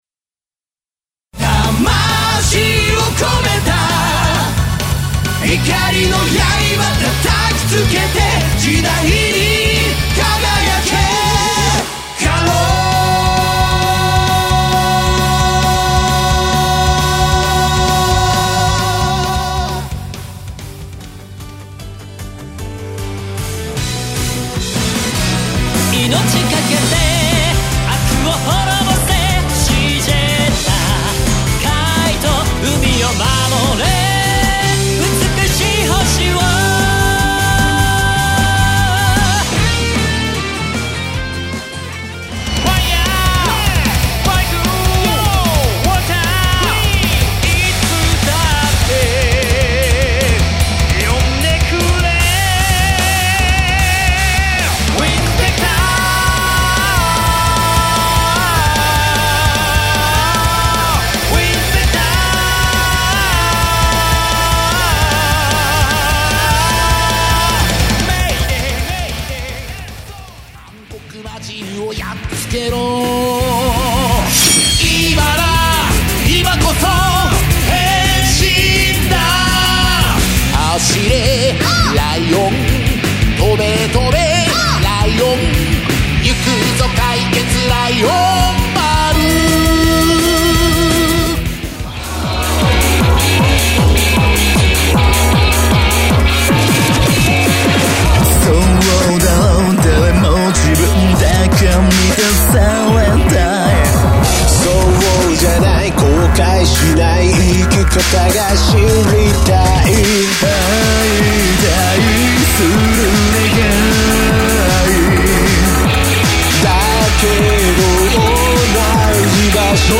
特撮アレンジフルボーカルアレンジＣＤ
コーラス
Hard Fishing Techno MIX
ギター